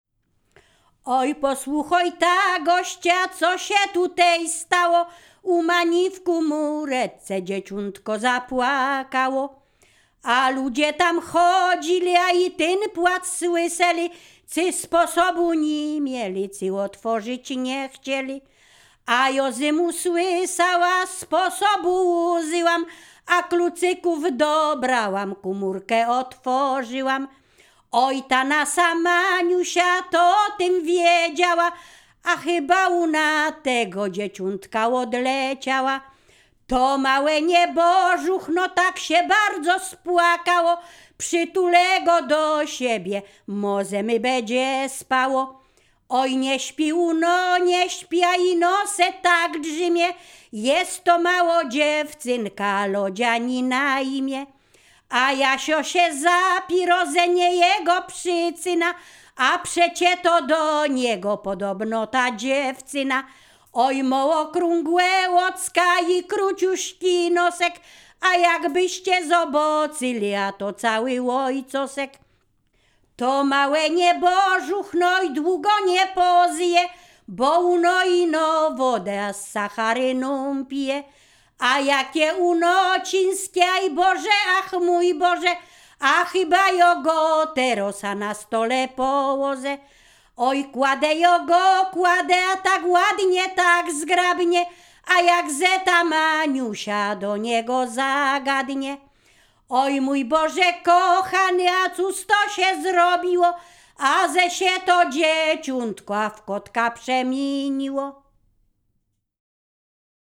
Ziemia Radomska
województwo mazowieckie, powiat przysuski, gmina Rusinów, wieś Brogowa
liryczne miłosne weselne wesele przyśpiewki